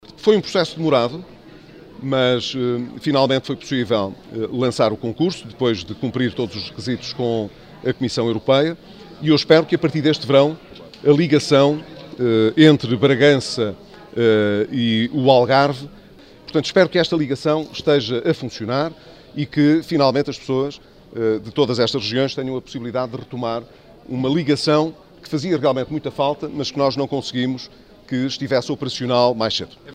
O primeiro-ministro, Pedro Passos Coelho congratula-se com o regresso do avião a Bragança. Pedro Passos Coelho, visitou a Expo Trás-os-Montes, no sábado, onde admitiu aos jornalistas, a abertura do concurso para a ligação aérea da região foi um processo demorado.